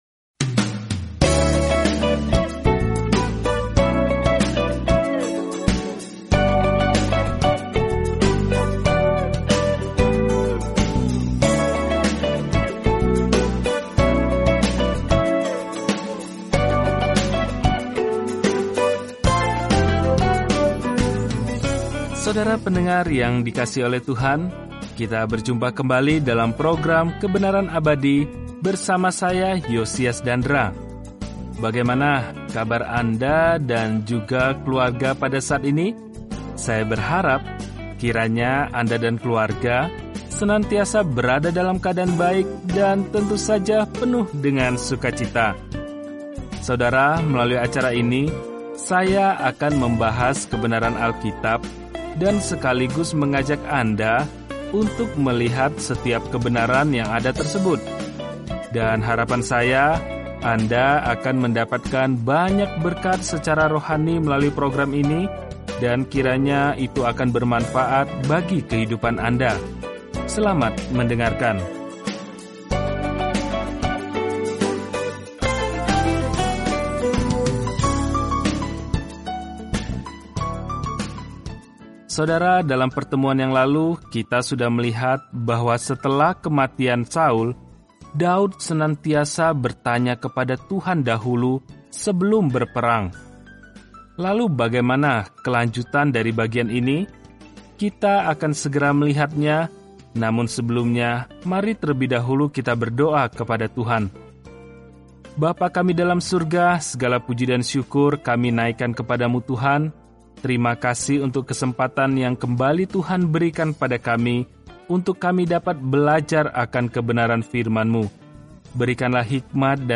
Firman Tuhan, Alkitab 2 Samuel 4 Hari 2 Mulai Rencana ini Hari 4 Tentang Rencana ini Kisah hubungan Israel dengan Tuhan berlanjut dengan diperkenalkannya para nabi pada daftar bagaimana Tuhan terhubung dengan umat-Nya. Telusuri 2 Samuel setiap hari sambil mendengarkan pelajaran audio dan membaca ayat-ayat tertentu dari firman Tuhan.